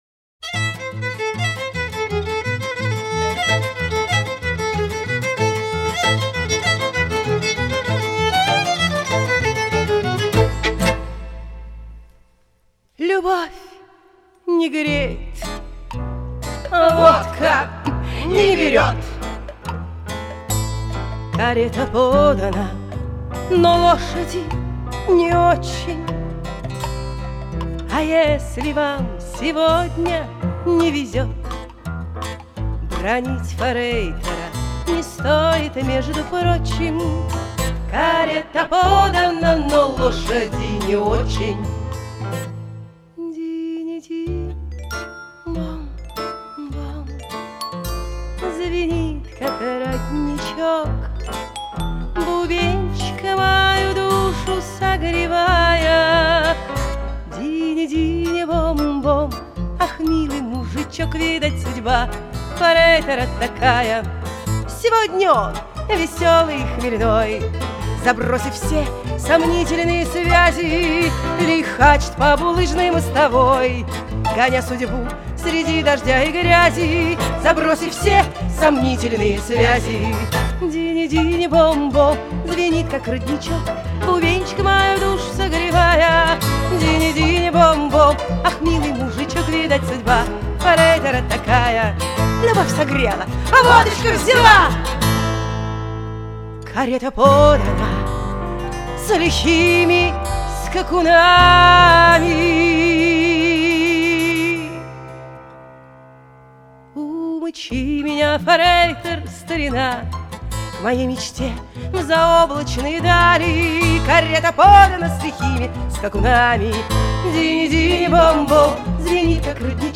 И ЕЩЕ ПАРОЧКА ПЕСЕН В ЭТОМ ЖЕ ИСПОЛНЕНИИ